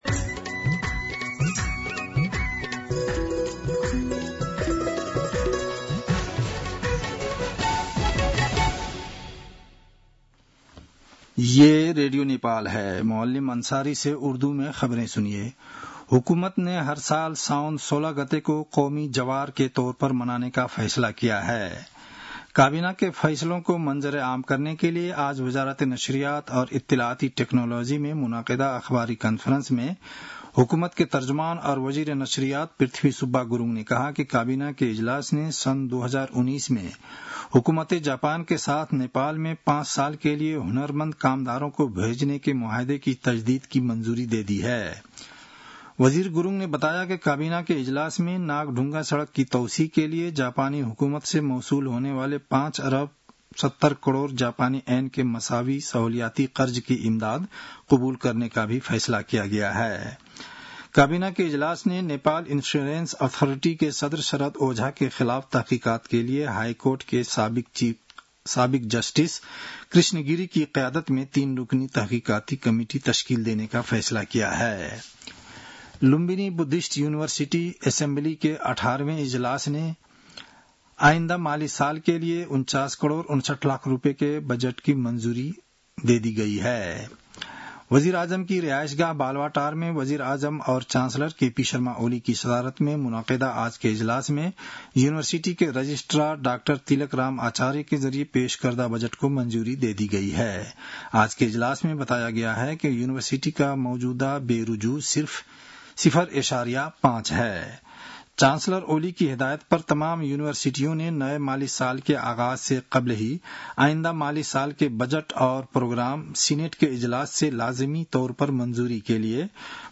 उर्दु भाषामा समाचार : ३२ असार , २०८२